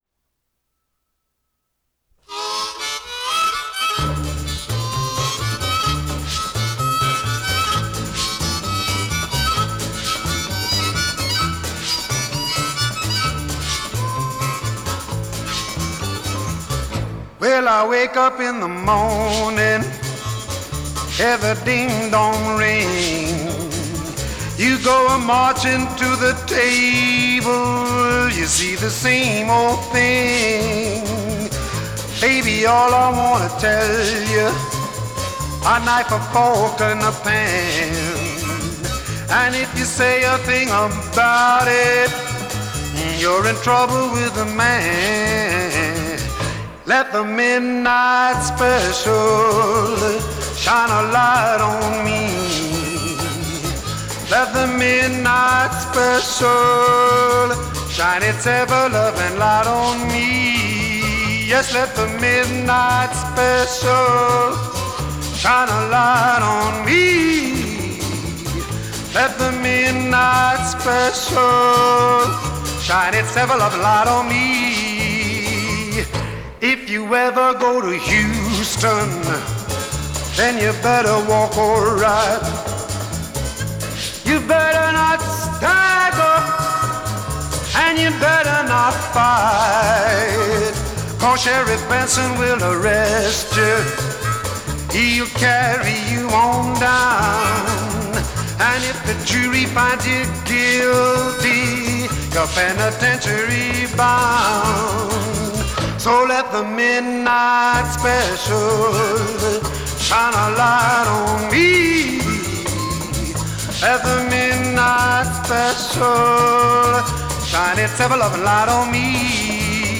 harmonica — transferred from tape in DSD256, DXD & PCM.